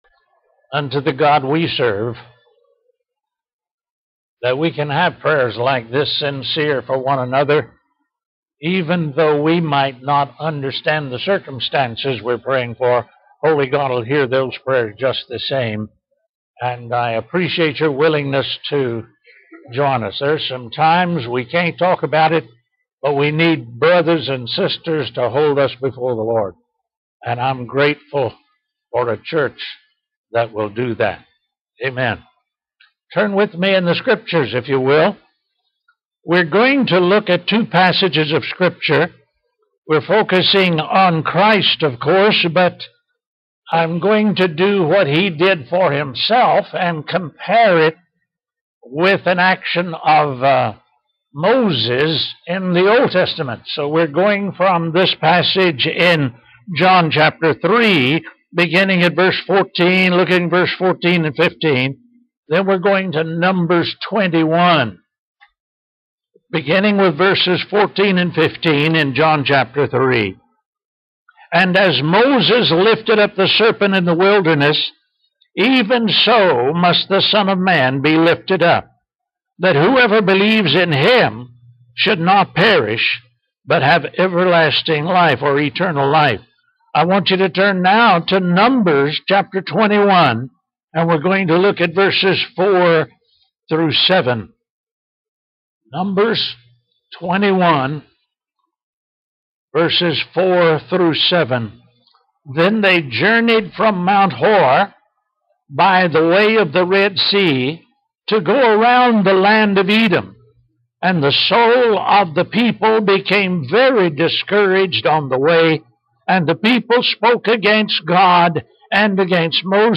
Sermons - Hillsdale Baptist Church